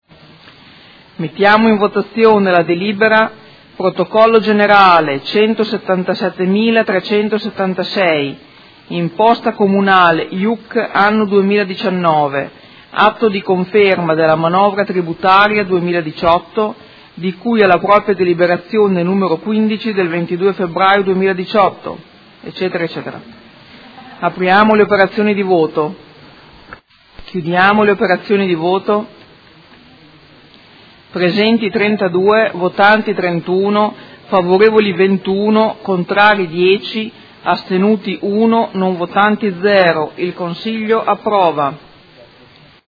Presidente
Seduta del 20/12/2018.